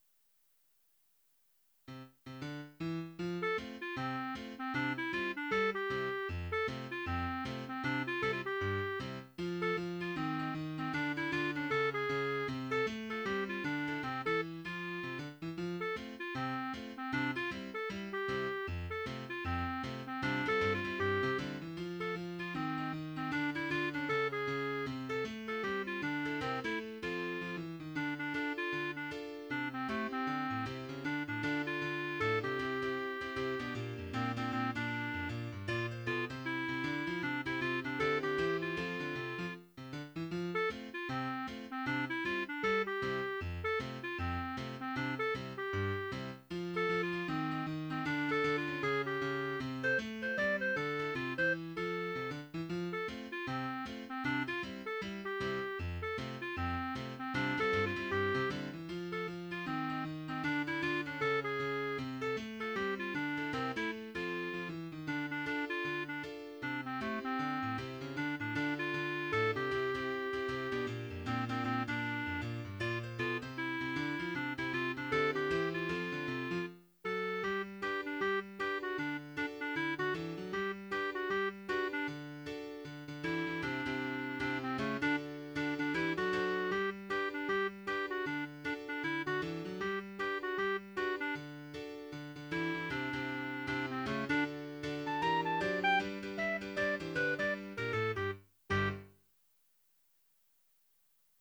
Title Little Bop III Opus # 324 Year 0000 Duration 00:01:41 Self-Rating 3 Description The first two have proven quite popular, which motivates me to continue the series. Perhaps I should buy stock in a reed company. mp3 download wav download Files: wav mp3 Tags: Duet, Piano, Clarinet Plays: 1563 Likes: 0